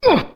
hurt.mp3